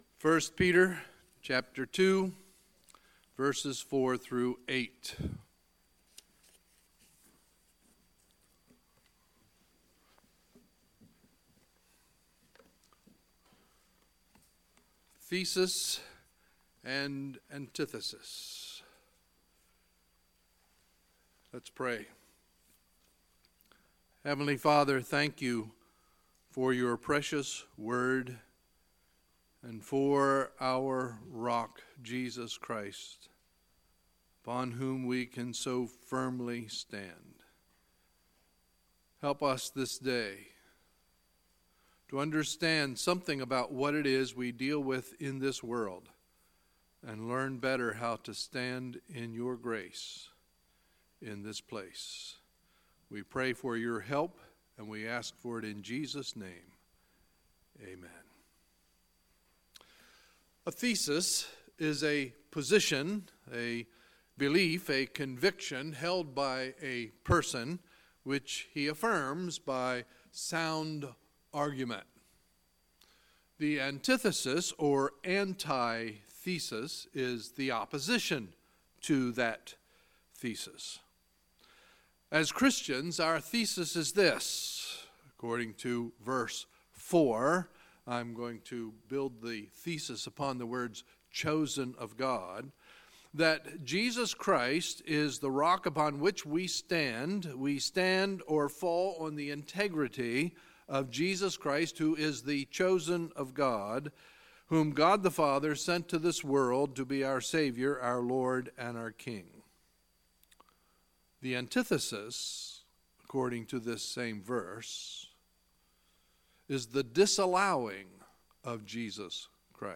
Sunday, April 15, 2018 – Sunday Morning Service